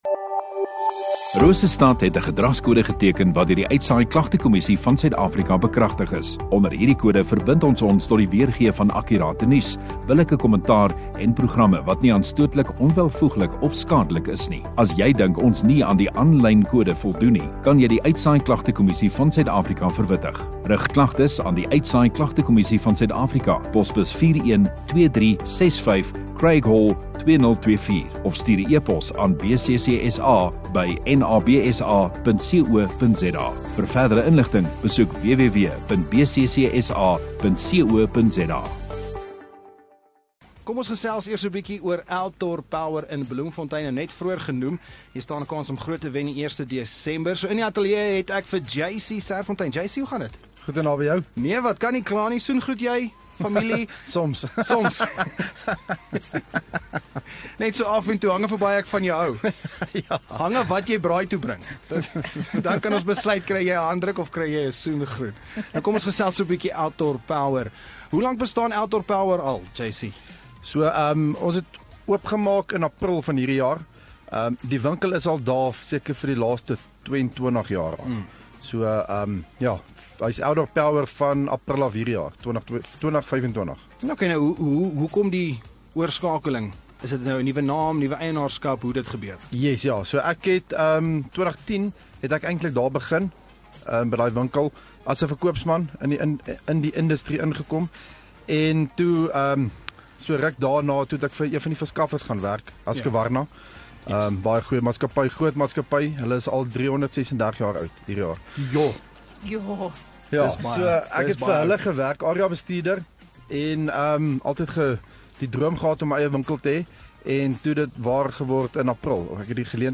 Radio Rosestad View Promo Continue Radio Rosestad Install Rosestad Onderhoude 13 Nov Outdoor Power Bloemfontein